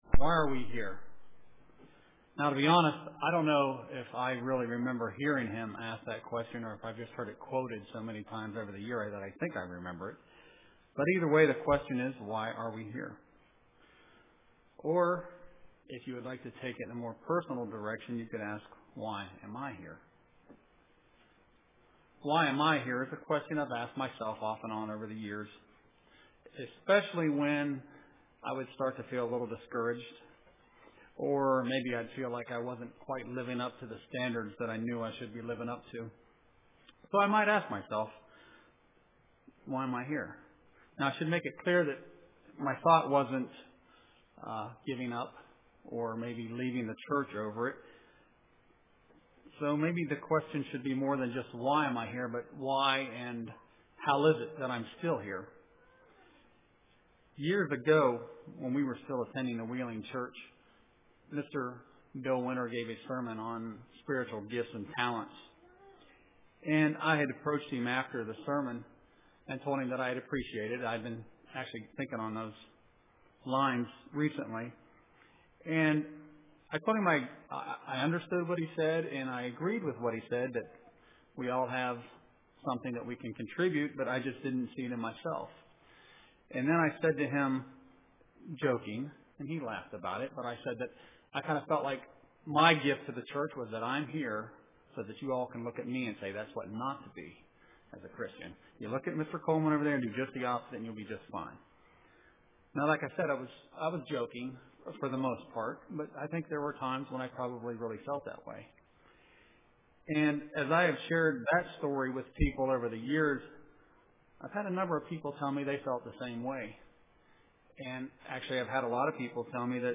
Print Why am I Here UCG Sermon Studying the bible?